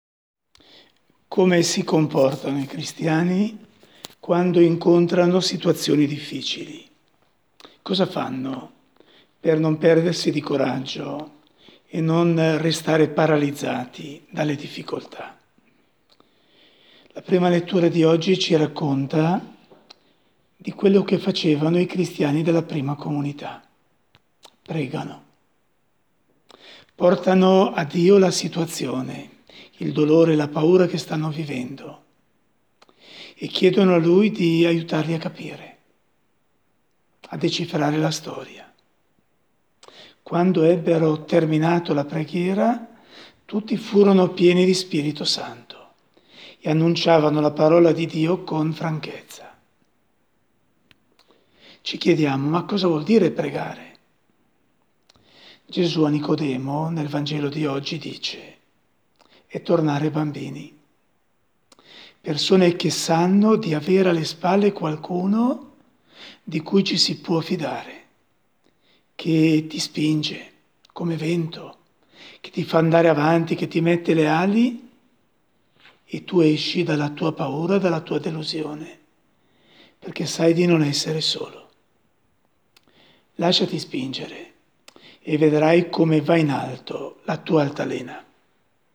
Commento alle letture di lunedì 20 aprile